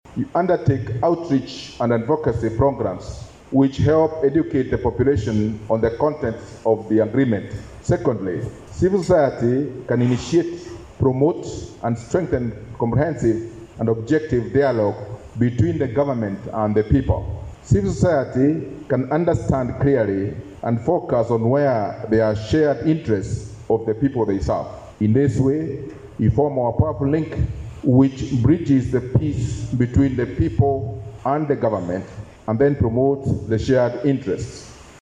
Maj. Gen. Gituai was addressing a four-day consultative meeting for stakeholders of the peace agreement held in Bor.